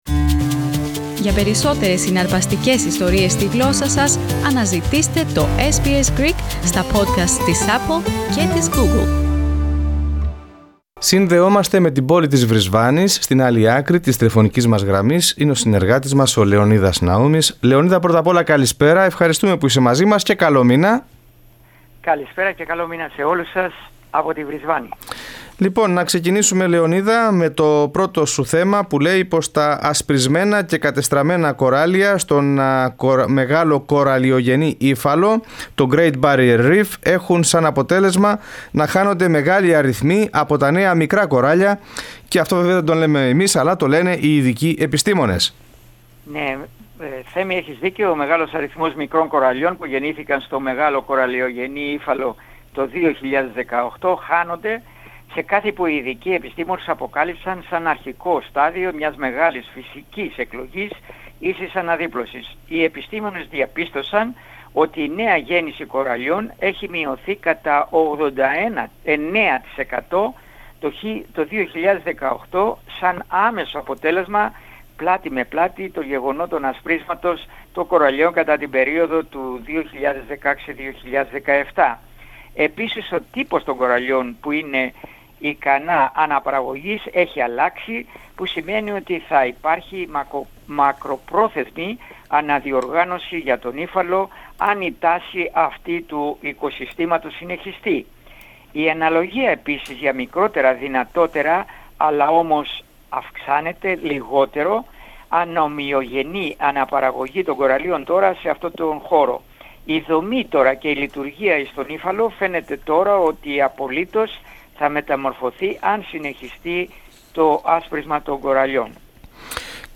SBS Greek weekly correspondence from Queensland's Brisbane.